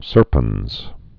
(sûrpənz, -pĕnz)